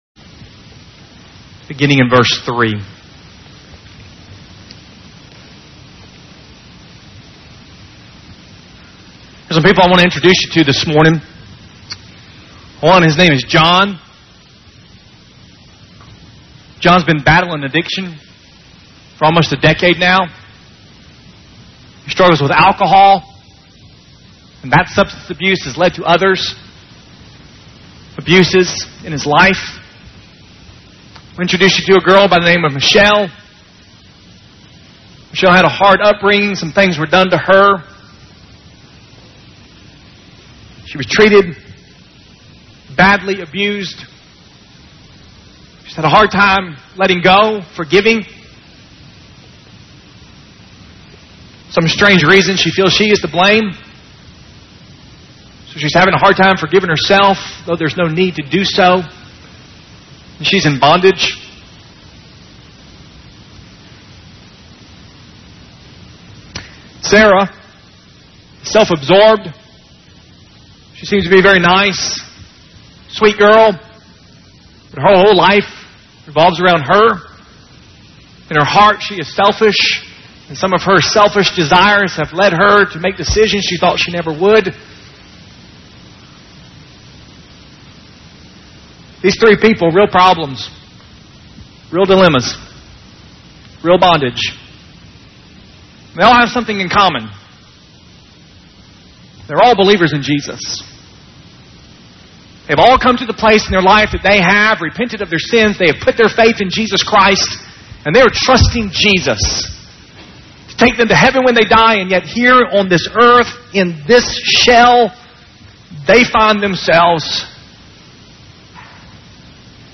First Baptist Church, Lebanon, Missouri - Sermon